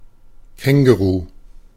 Ääntäminen
Synonyymit hoodie roo bunny hug macropod kangaroo jacket Ääntäminen US : IPA : [ˌkæŋ.ɡə.ˈɹuː] UK : IPA : /kaŋɡəˈɹuː/ US : IPA : /ˌkæŋɡəˈɹu/ Tuntematon aksentti: IPA : /ˌkæŋgəˈru/ Lyhenteet ja supistumat 'roo